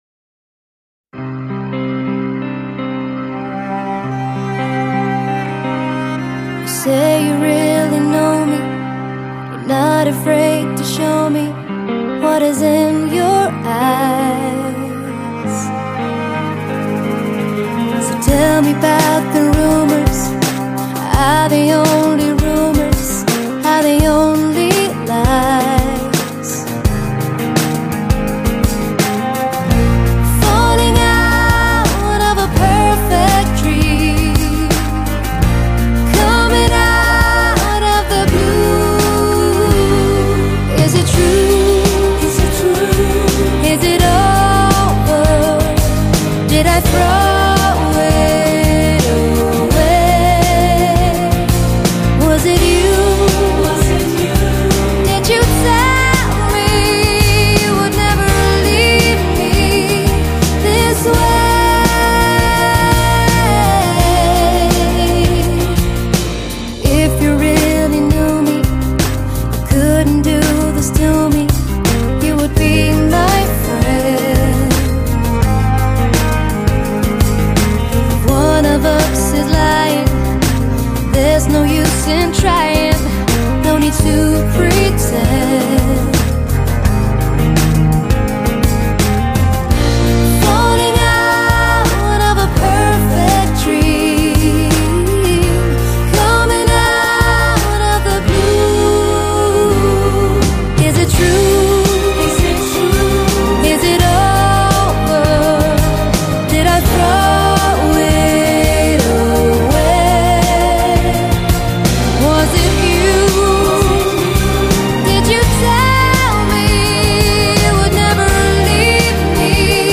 整张碟偏向与流行和软摇滚